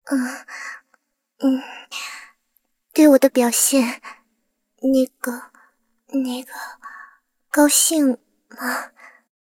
追猎者MVP语音.OGG